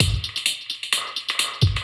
Index of /musicradar/dub-designer-samples/130bpm/Beats
DD_BeatC_130-03.wav